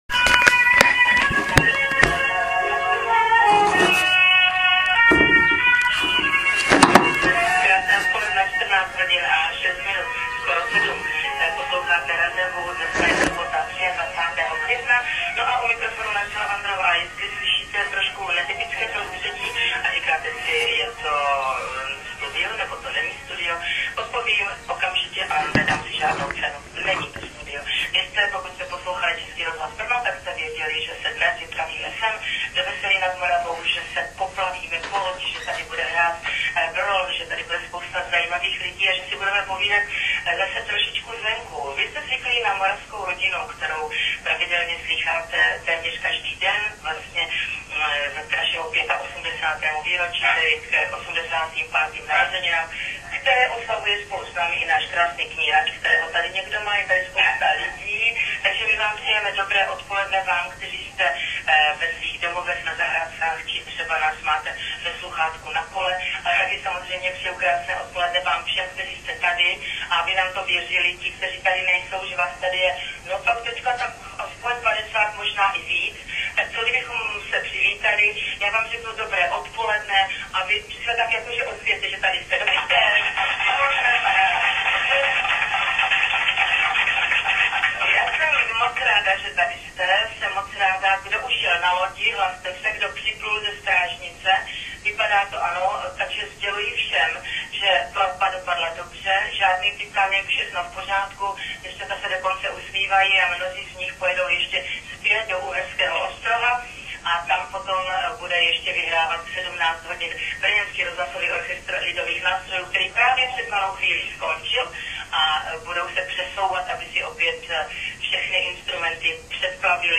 Přidal jsem i zvukový záznam pořadu Českého rozhlasu Brno, který byl odvysílán v sobotu mezi druhou a čtvrtou právě z Baťova kanálu.
Omlouvám se ale za kvalitu nahrávky, nahrával jsem totiž vše přímo z rádia na diktafon a poté to ještě přehrával na další diktafon, z něhož šlo dát nahrávku na internet.